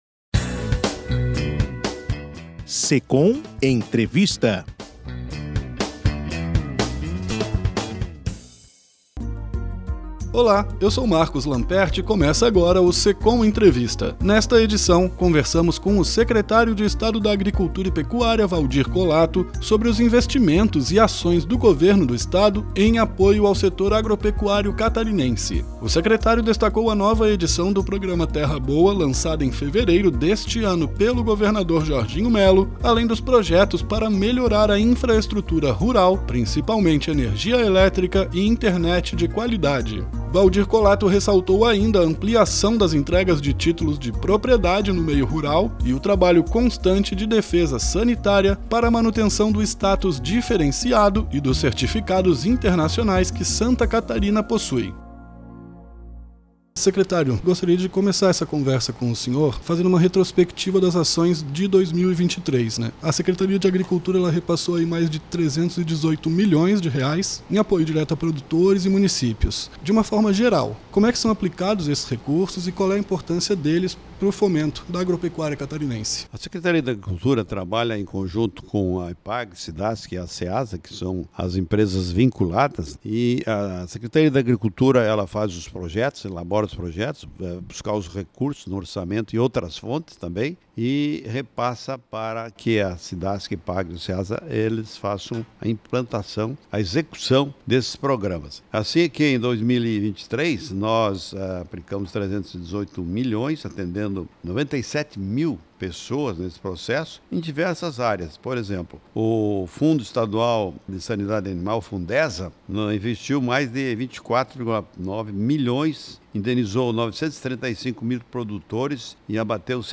SECOM ENTREVISTA: secretário da Agricultura fala dos investimentos e ações em apoio aos setor agropecuário catarinense
Nesta edição, conversamos com o secretário de Estado da Agricultura e Pecuária, Valdir Colatto, sobre os investimentos e ações do Governo do Estado em apoio aos setor agropecuário catarinense.
SECOM-Entrevista-Secretario-da-Agricultura-Valdir-Colatto.mp3